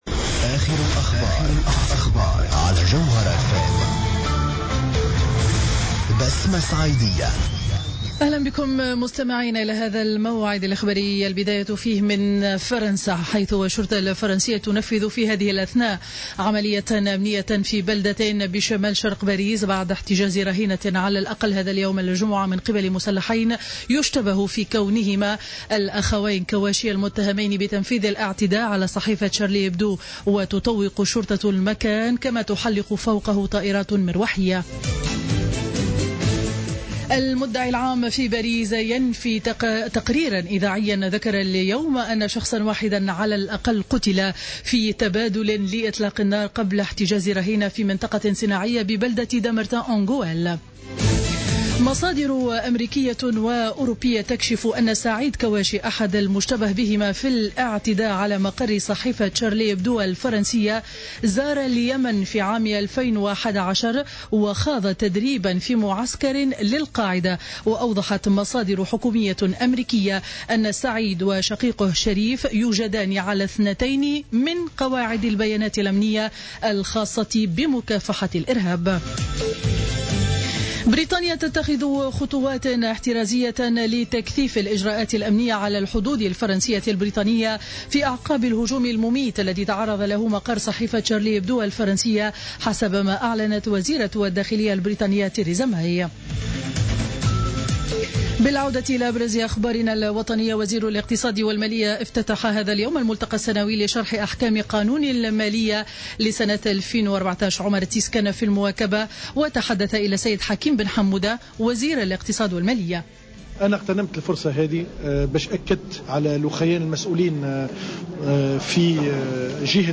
نشرة أخبار منتصف النهار ليوم الجمعة 09-01-15